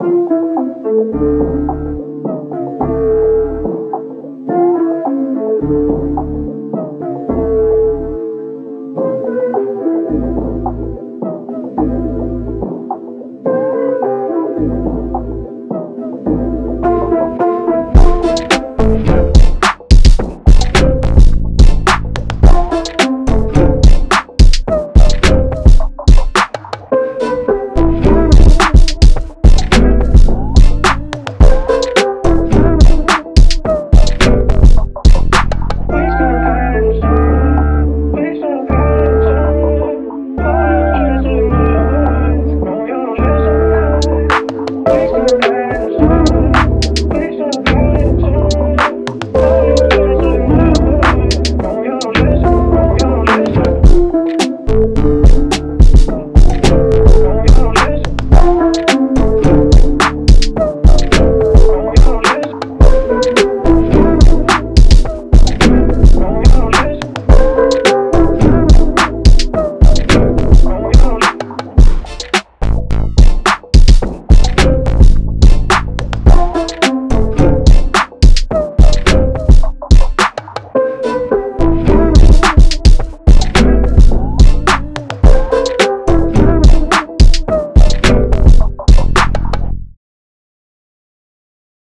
Amin 107